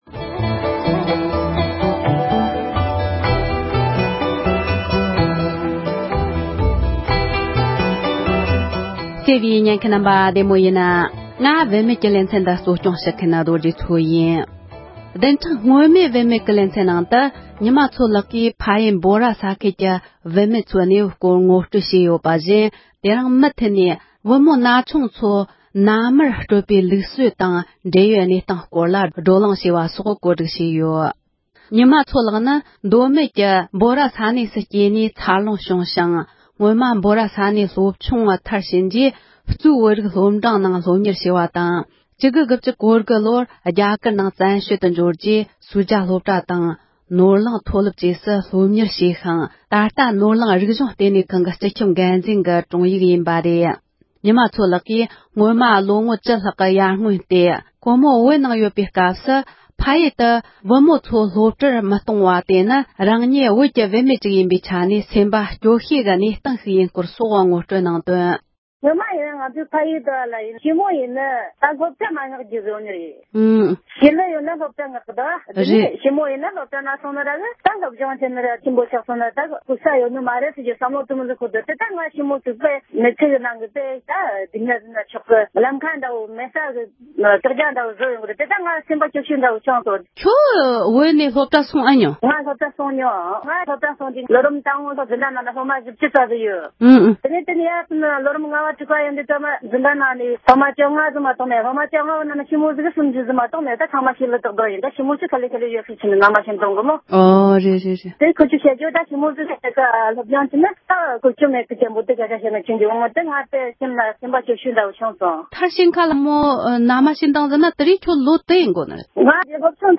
སྒྲ་ལྡན་གསར་འགྱུར།
བོད་མོ་འགས་གནས་ཚུལ་ངོ་སྤྲོད་བྱས་པ་ཁག་ཅིག་